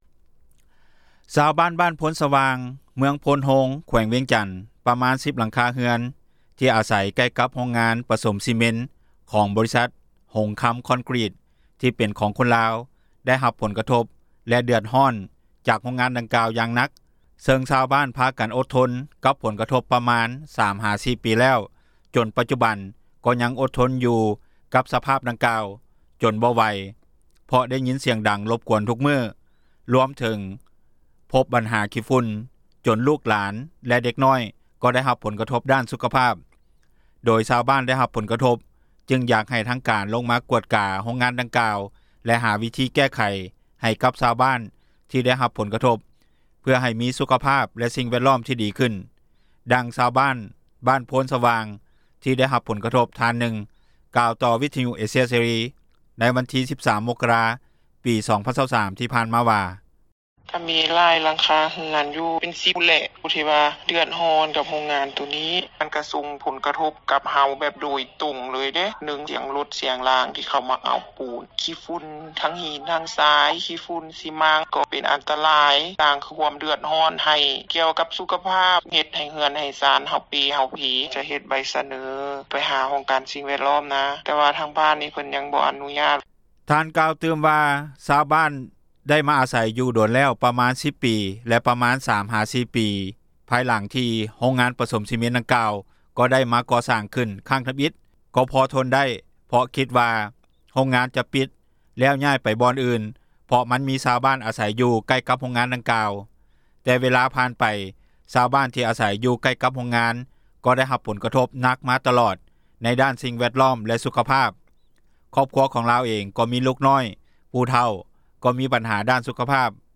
ດັ່ງຊາວບ້ານ ບ້ານໂພນສະຫວ່າງ ທີ່ໄດ້ຮັບຜົນກະທົບ ທ່ານນຶ່ງ ກ່າວຕໍ່ວິທຍຸ ເອເຊັຽ ເສຣີ ໃນວັນທີ 13 ມົກຣາ 2023 ທີ່ຜ່ານມາວ່າ: